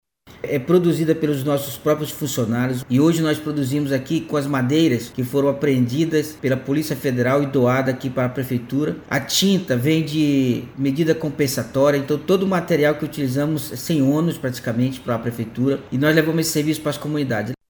Sonora-2-Altervi-Moreira-–-secretario-Semulsp.mp3